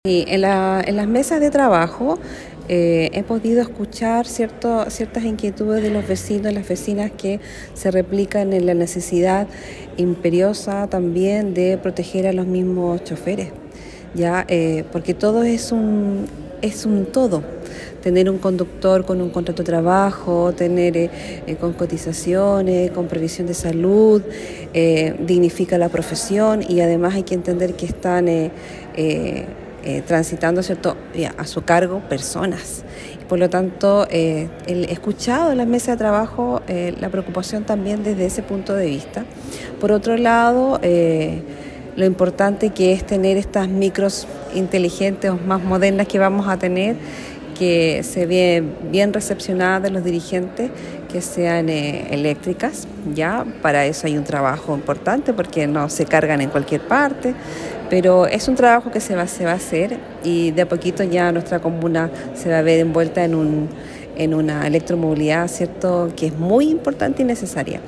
La Concejala Cecilia Canales, señaló que durante las mesas de trabajo que se desarrollaron, se pudo evidenciar que la comunidad también está enfocada en las condiciones labores que tienen los conductores del transporte público.